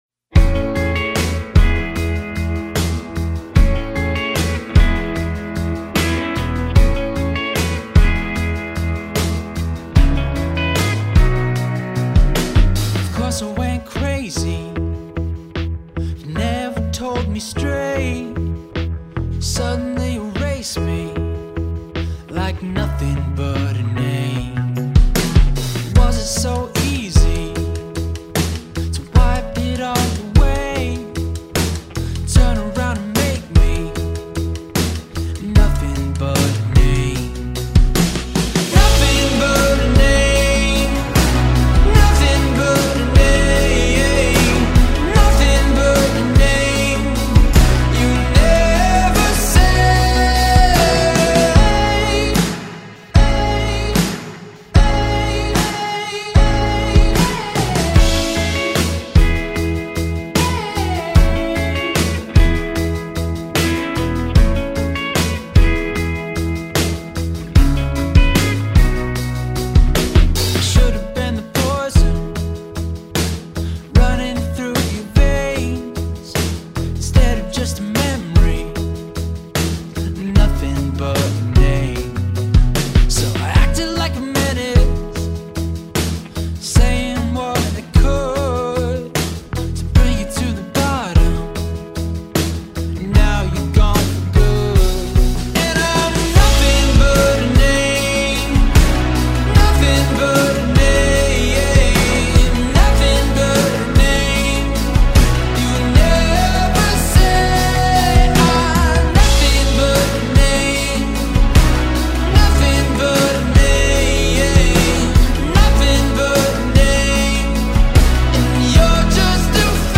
singer-songwriter
alternative pop